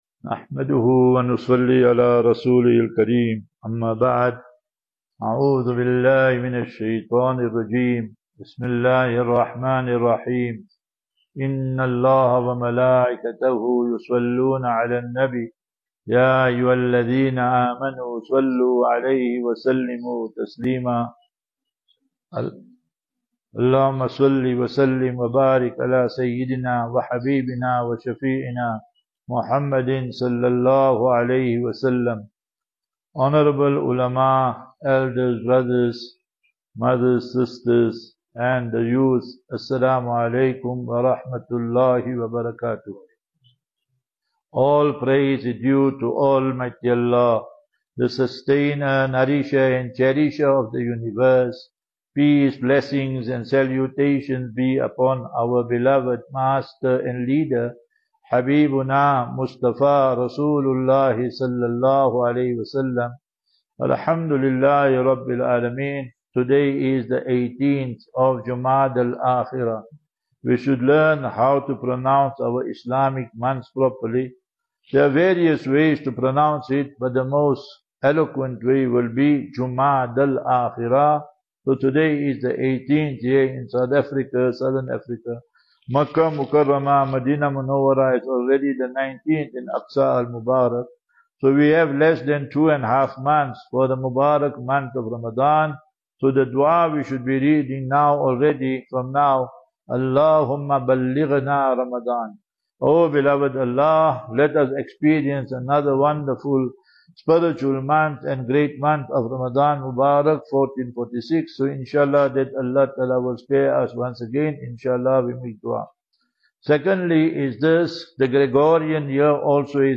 20 Dec 20 Dec 24 - Jumah lecture from Trichardt